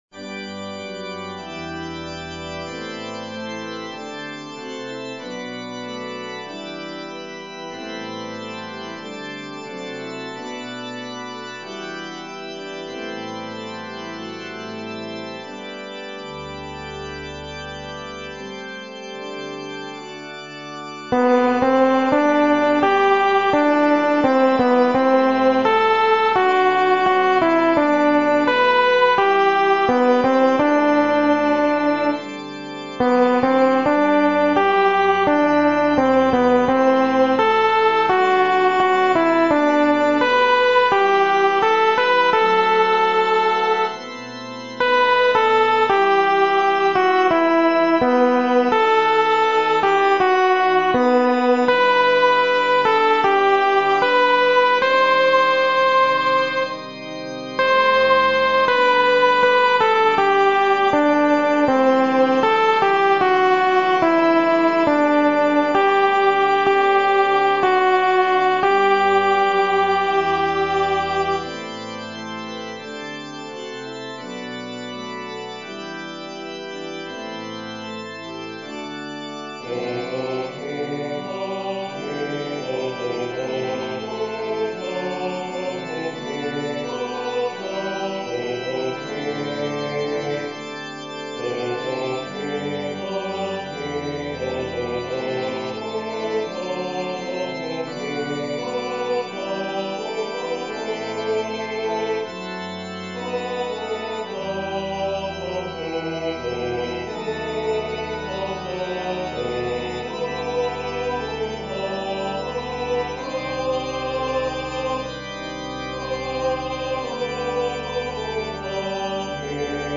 ソプラノ（フレットレスバス音）